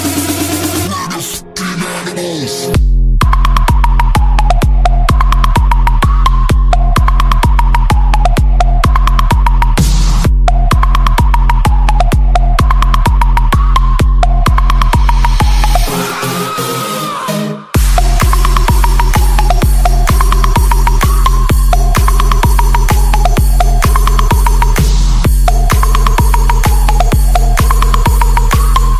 animals-martin_14110.mp3